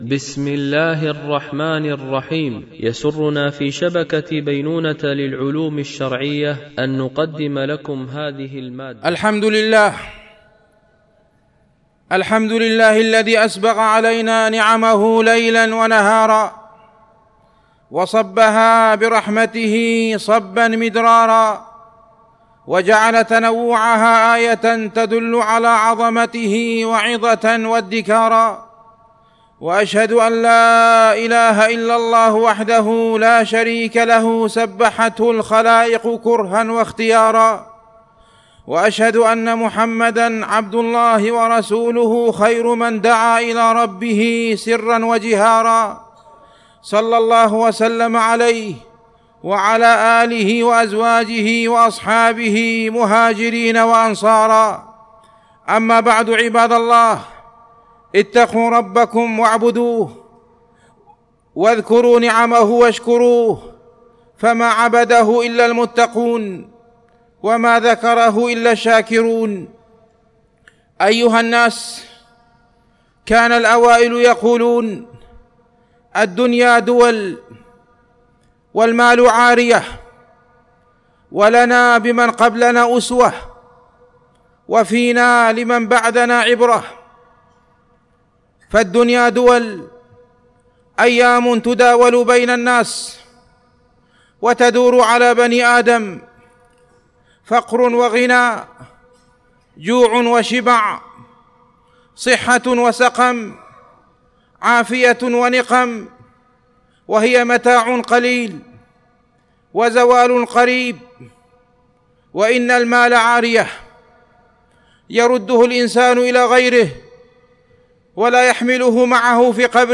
التنسيق: MP3 Mono 44kHz 96Kbps (VBR)